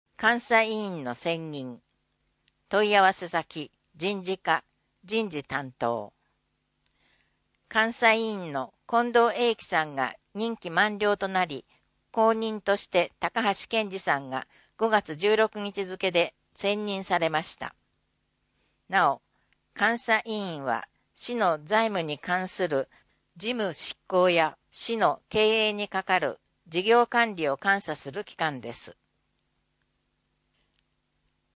声の広報つるがしまは、（福）鶴ヶ島市社会福祉協議会の協力のもと「鶴ヶ島音訳ボランティアサークルせせらぎ」の皆さんが「広報つるがしま」の内容を音訳し、「デイジー鶴ヶ島」の皆さんがデイジー版CDを製作して、目の不自由な方々へ配布をしています。